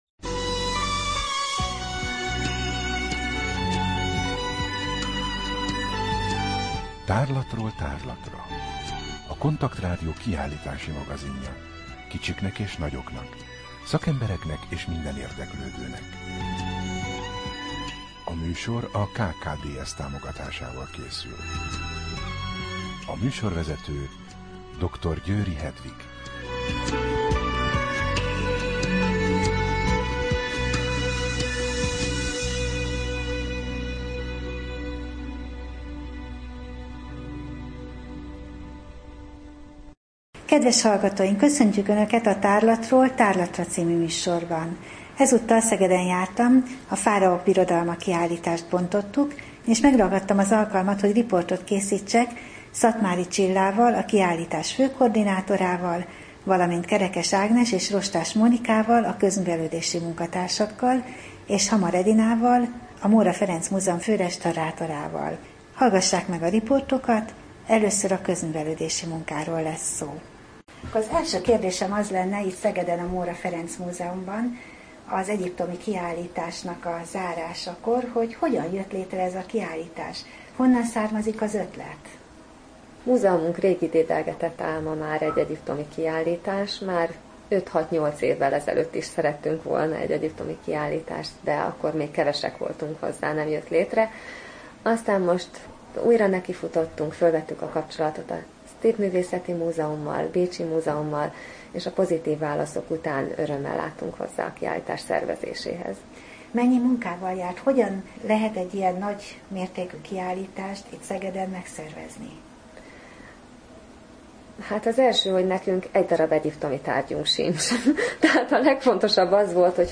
Rádió: Tárlatról tárlatra Adás dátuma: 2015, January 15 Tárlatról tárlatra / KONTAKT Rádió (87,6 MHz) 2015. január 15. A műsor felépítése: I. Kaleidoszkóp / kiállítási hírek II. Bemutatjuk / A fáraók birodalma, Móra Ferenc Múzeum – Szeged